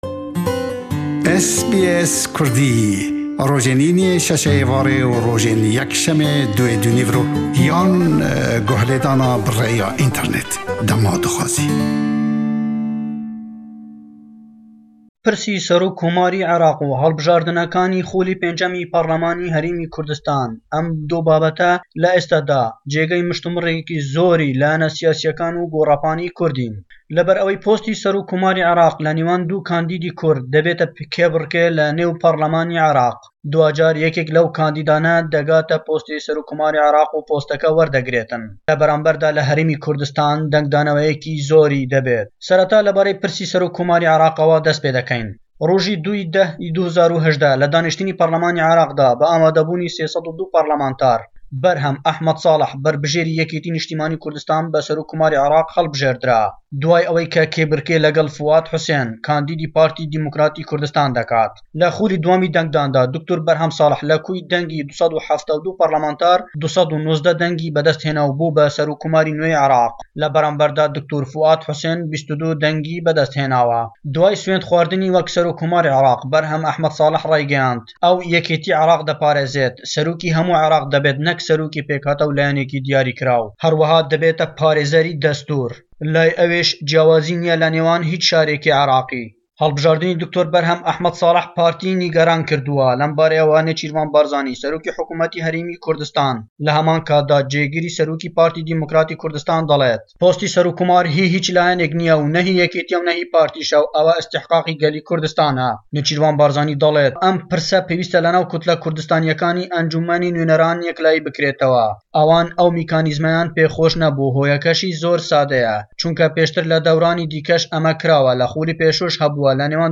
Raporta peyamnêr